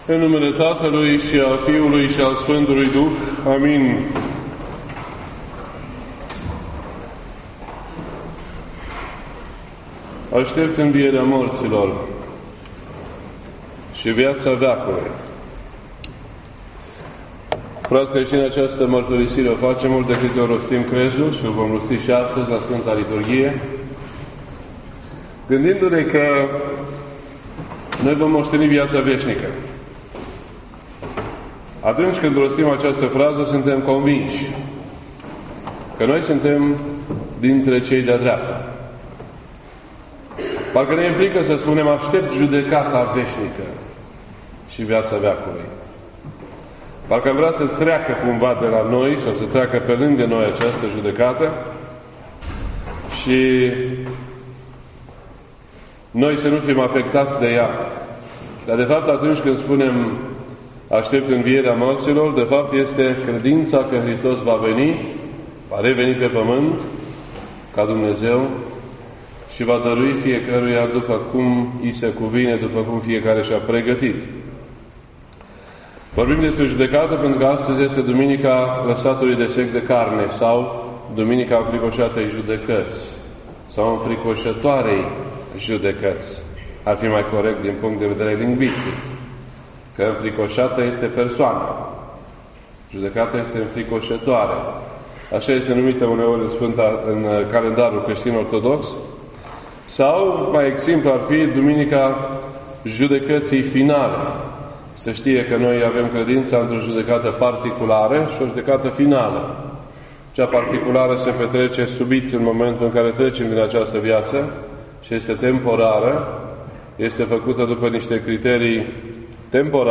This entry was posted on Sunday, February 11th, 2018 at 1:42 PM and is filed under Predici ortodoxe in format audio.